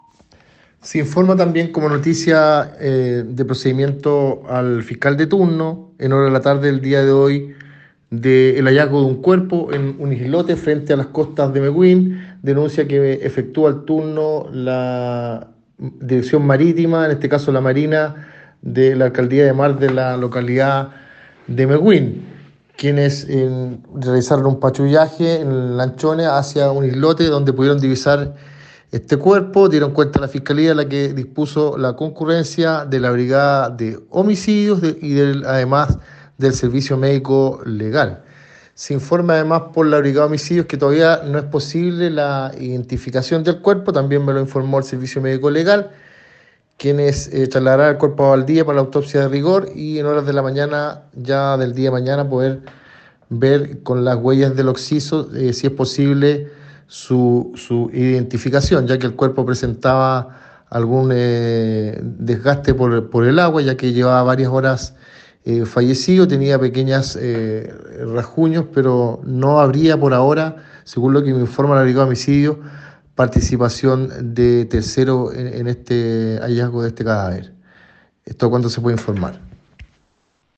Cuña fiscal de turno, Fiscal de turno de la Unidad de Flagrancia de la Fiscalía de Los Ríos, Sergio Carmona.